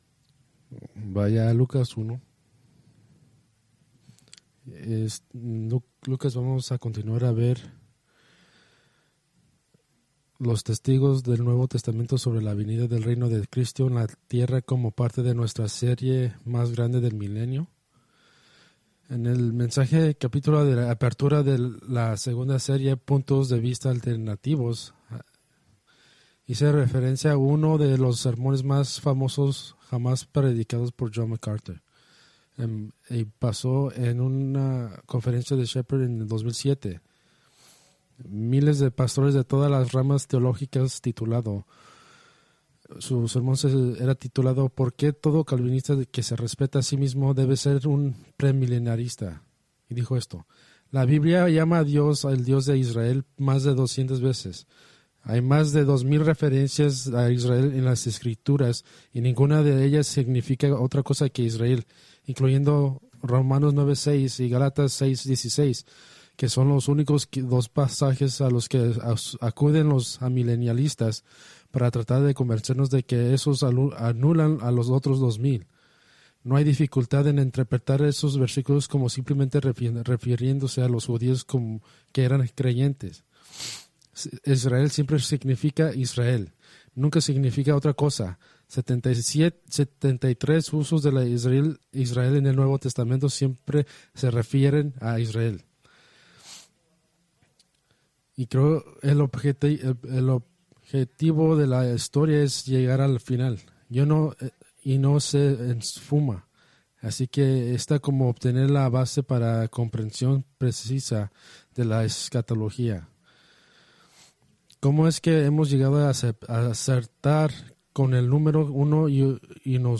Preached November 10, 2024 from Escrituras seleccionadas